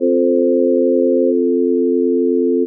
It plays four channels at the same time: the first channel plays a C, the second an F, the third an A, and the fourth a C in the next octave. This final note is shorter than the others, so it ends first; the remaining three notes keep playing the harmonious chord.